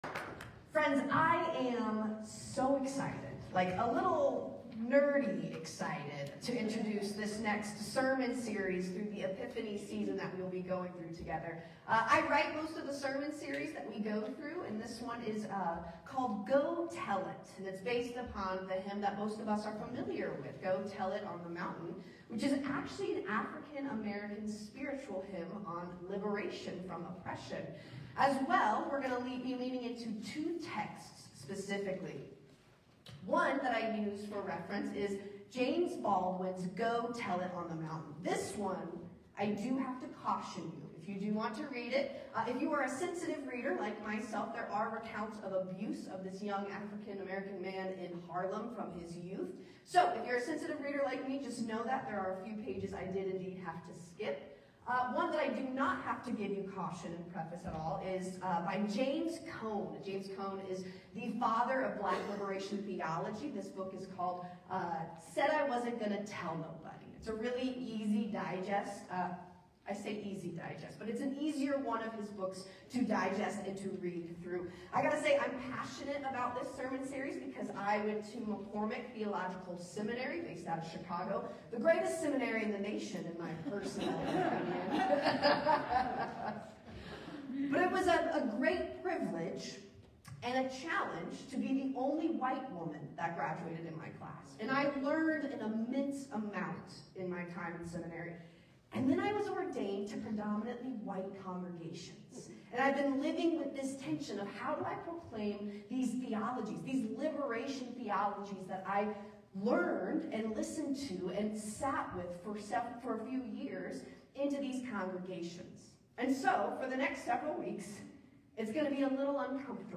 1/4/26 Sermon: Go Tell It to the World